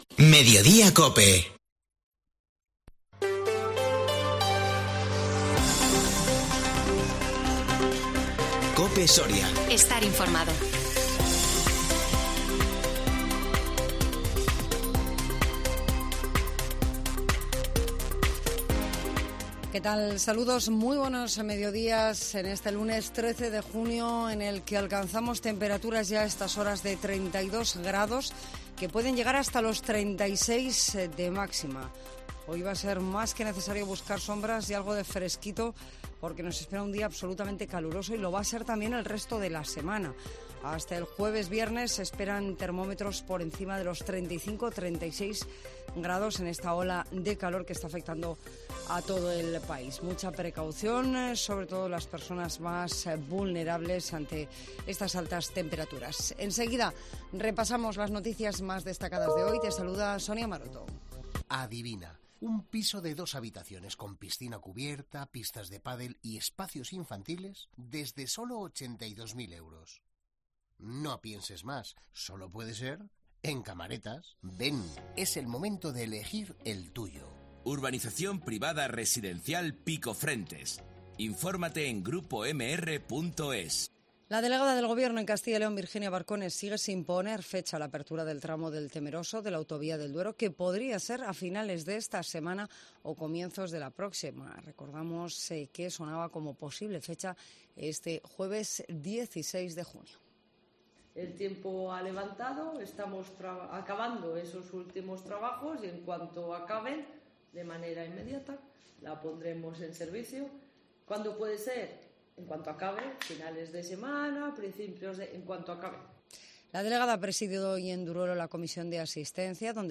INFORMATIVO MEDIODÍA COPE SORIA 13 JUNIO 2022